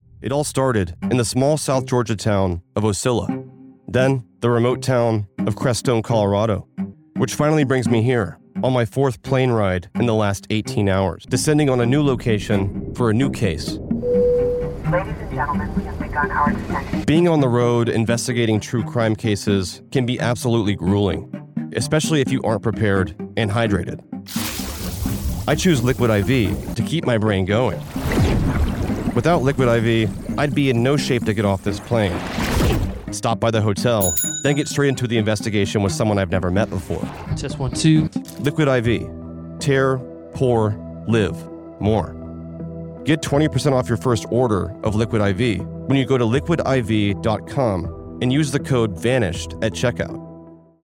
Social media followers even commented on the smoothness of the ad reads, reinforcing that the right partnership can take ads to new heights in a way that enchants the audience and corresponds to real, measurable full-funnel results.